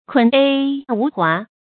悃愊無華 注音： ㄎㄨㄣˇ ㄅㄧˋ ㄨˊ ㄏㄨㄚˊ 讀音讀法： 意思解釋： 悃愊：至誠；華：浮夸。